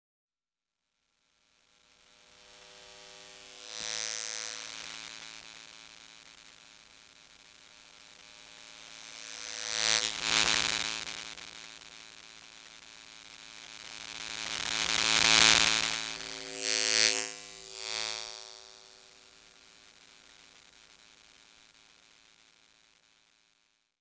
ElectricityBuzzL.wav